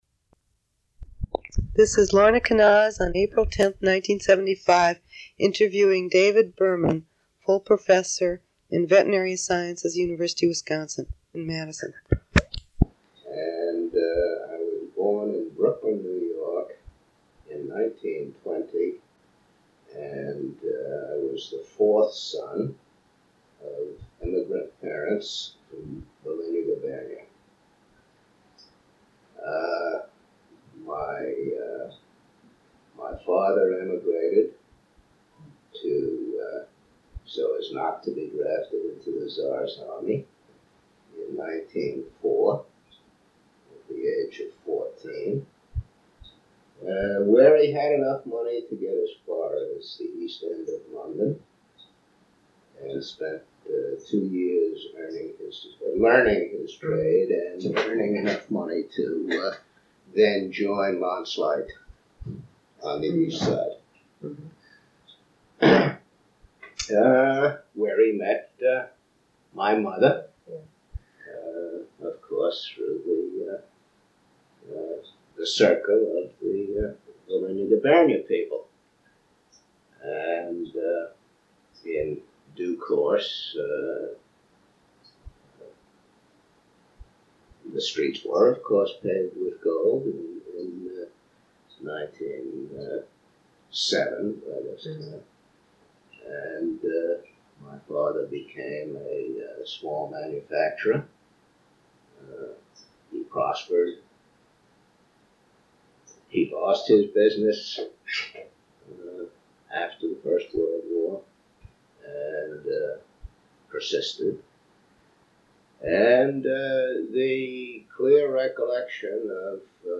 Wisconsin Historical Society Oral History Collections